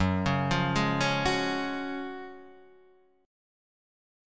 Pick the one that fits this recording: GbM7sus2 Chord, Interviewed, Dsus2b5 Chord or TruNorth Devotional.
GbM7sus2 Chord